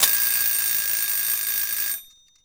timer.wav